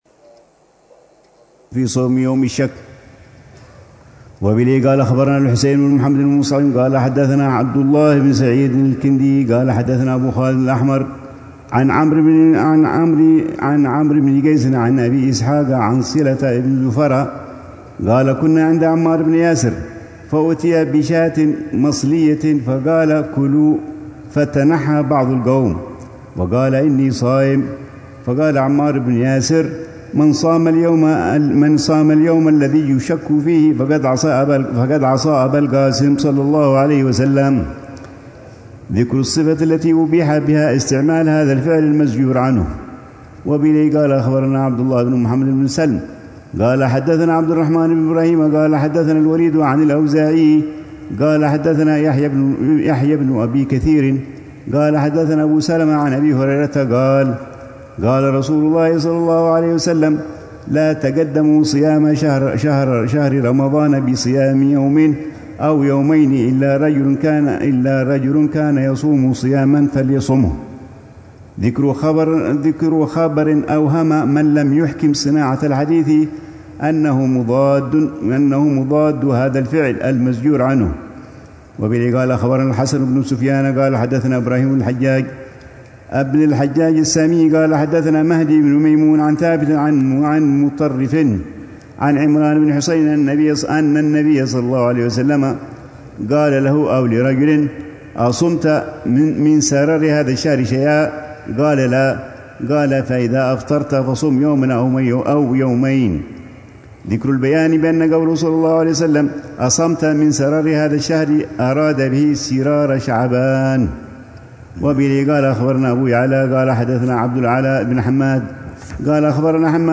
الروحة السادسة والعشرون بدار المصطفى أيام الست من شوال لعام 1446هـ ، وتتضمن شرح الحبيب العلامة عمر بن محمد بن حفيظ لكتاب الصيام من صحيح ابن حب